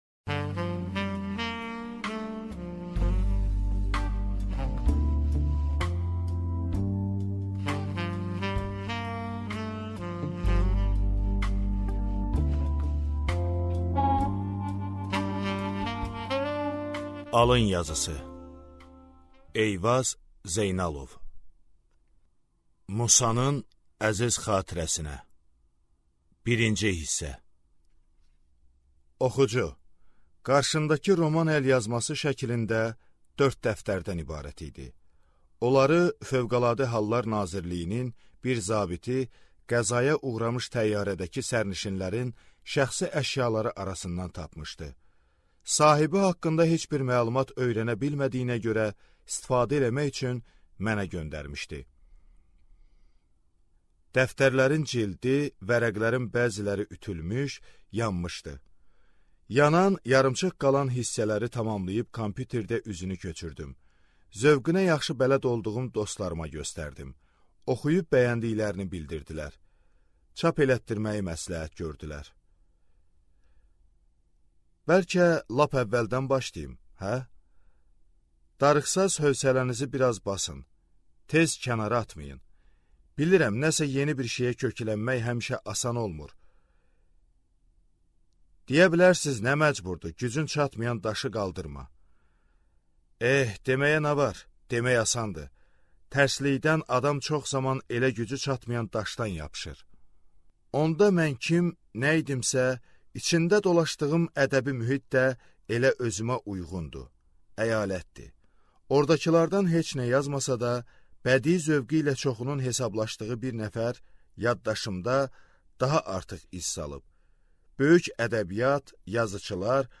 Аудиокнига Alın yazısı | Библиотека аудиокниг